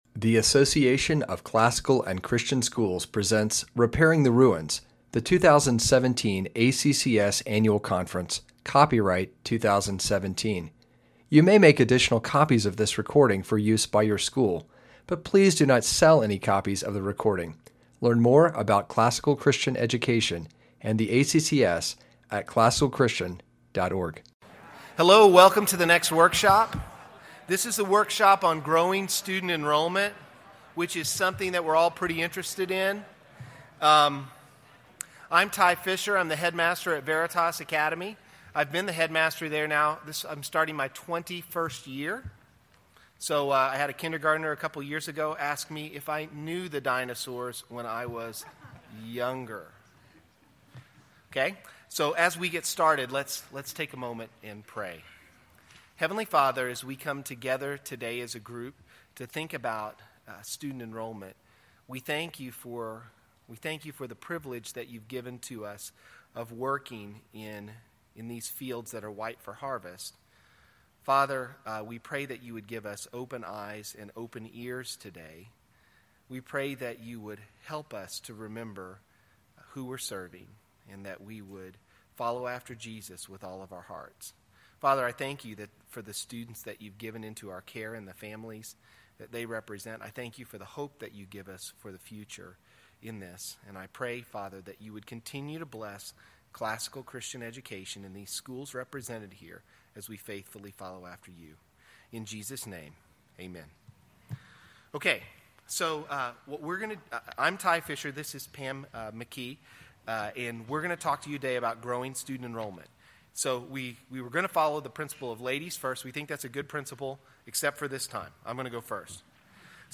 2017 Leaders Day Talk | 0:49:59 | Leadership & Strategic, Marketing & Growth
Attendees will spend the last part of the session discussing ways to implement these principles at school.